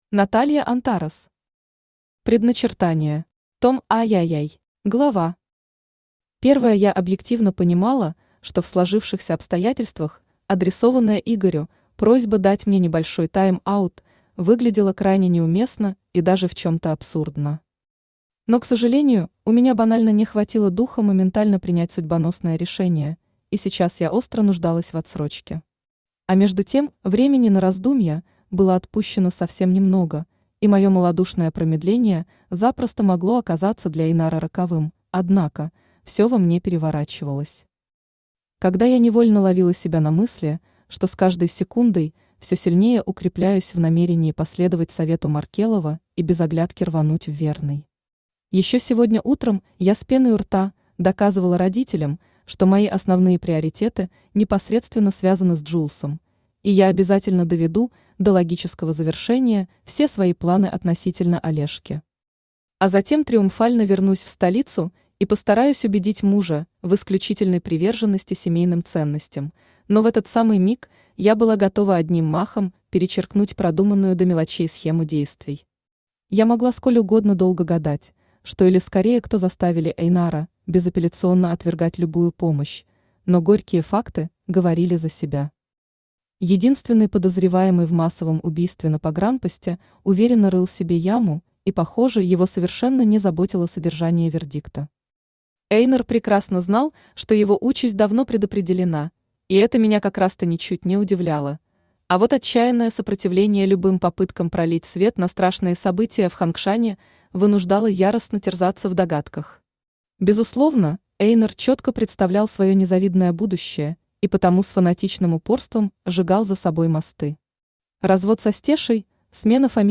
Аудиокнига Предначертание. Том III | Библиотека аудиокниг
Том III Автор Наталья Антарес Читает аудиокнигу Авточтец ЛитРес.